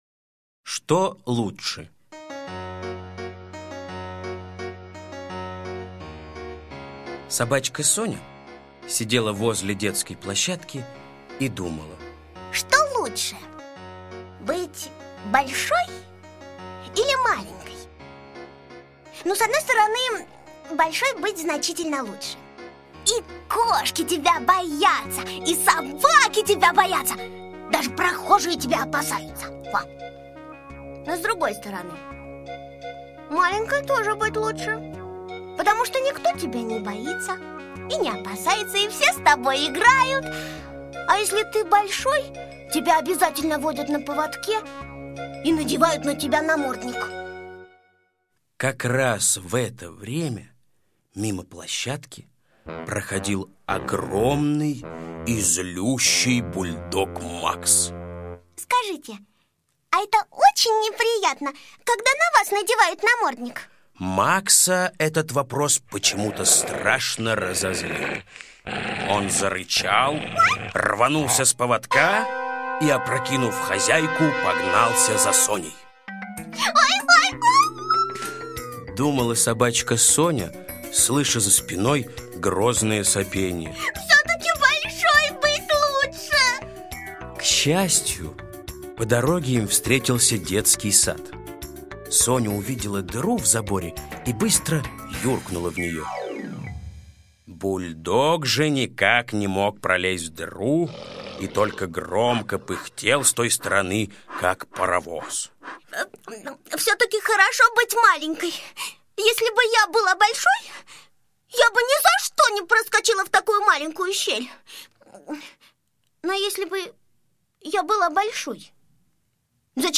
Что лучше? - аудиосказка Усачева А.А. Сказка про собачку Соню, которая никак не могла решить: лучше быть большой или маленькой собачкой.